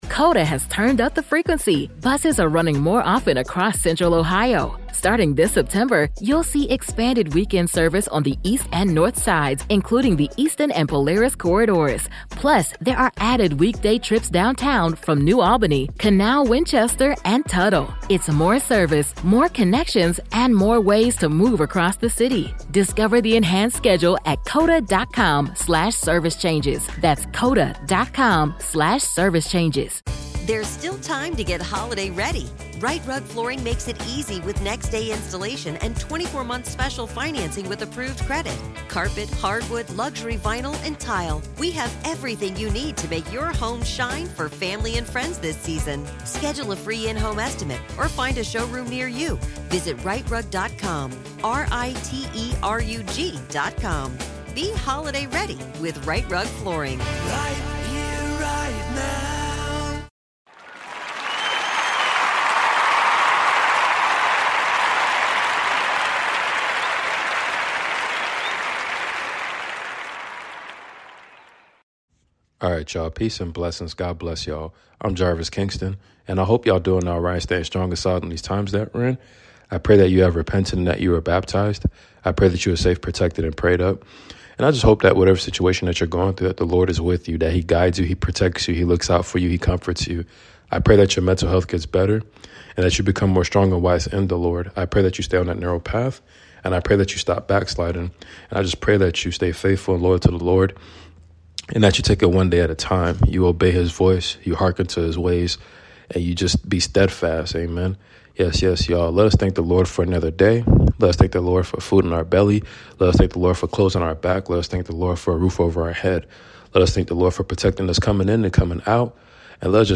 Matthew 6 & 7 reading ! Let’s meditate in the Word of God !!